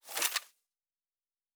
pgs/Assets/Audio/Sci-Fi Sounds/Weapons/Weapon 13 Foley 3.wav at 7452e70b8c5ad2f7daae623e1a952eb18c9caab4
Weapon 13 Foley 3.wav